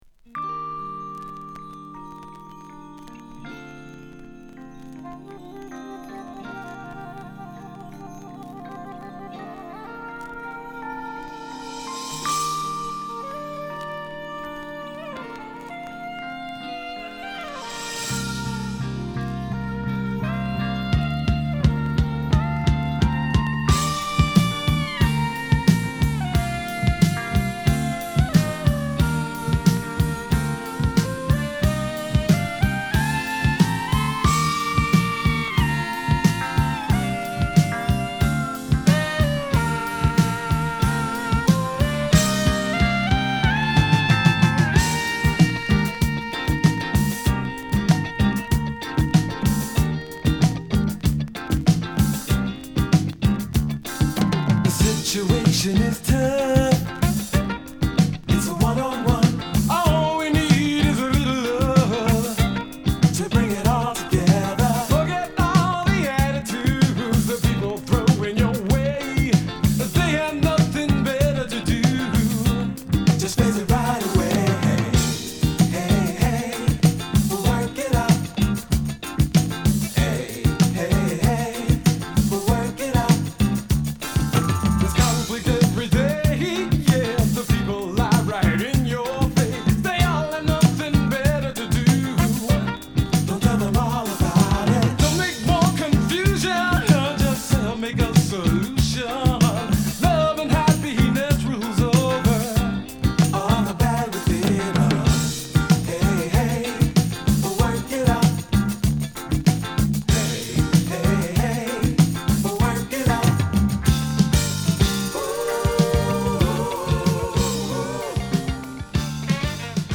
フィラデルフィア発のファンクバンド